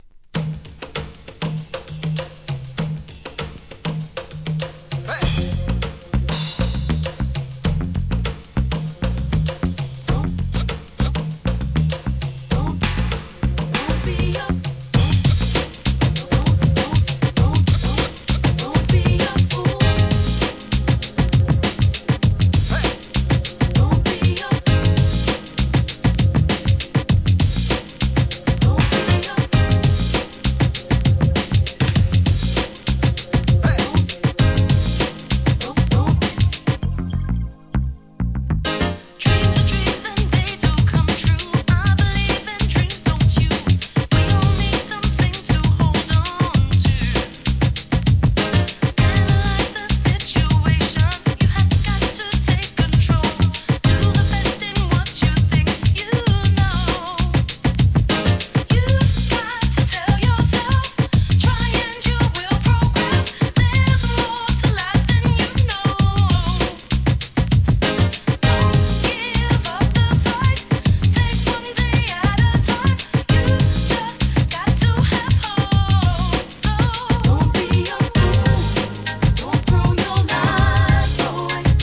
HIP HOP/R&B
テンポの良いHIP HOP BEAT、万人受けのメロディー、キャッチーなフックと三拍子揃ったUK R&Bの名作!